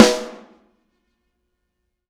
R_B Snare 02 - Close.wav